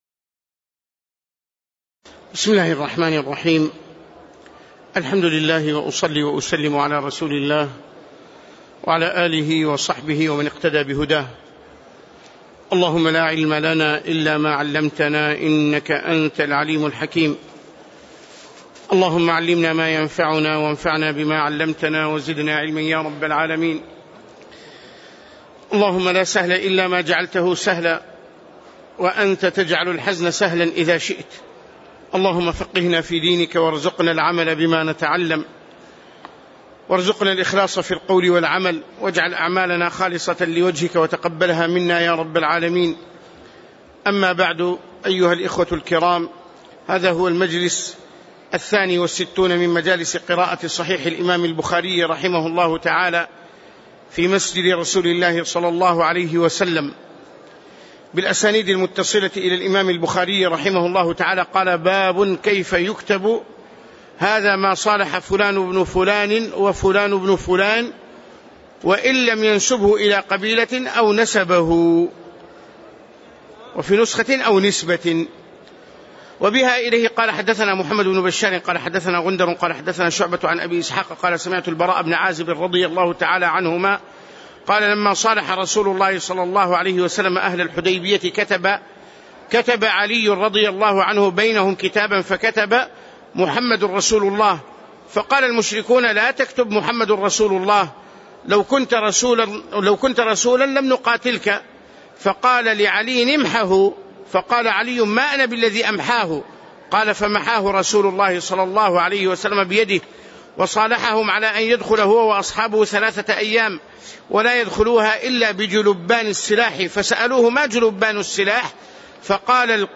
تاريخ النشر ٩ جمادى الأولى ١٤٣٨ هـ المكان: المسجد النبوي الشيخ